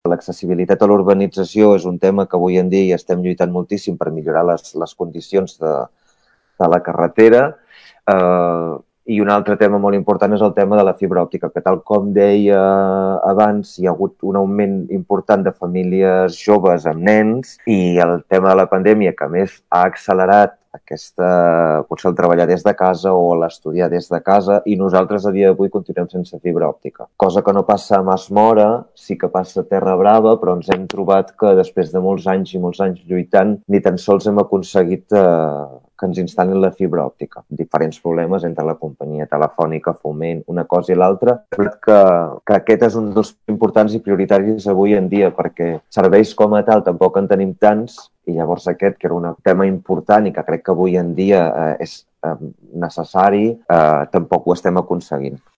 Tall de veu